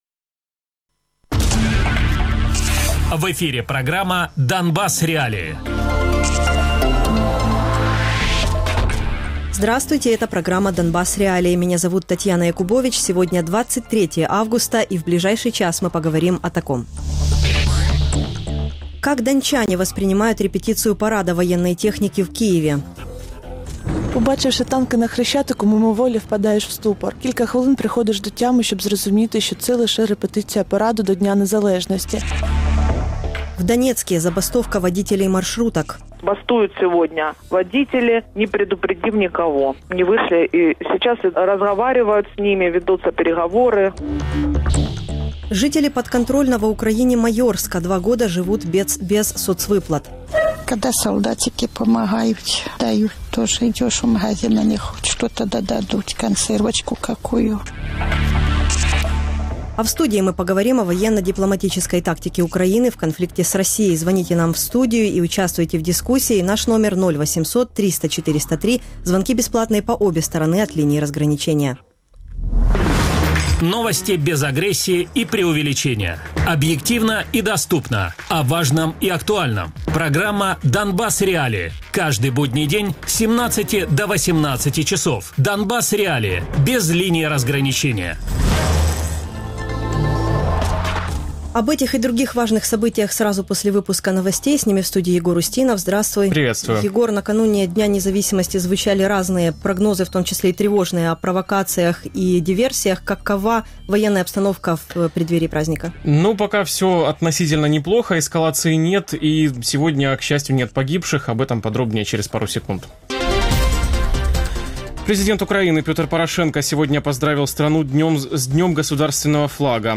Гости: Олег Шамшур, посол Украины во Франции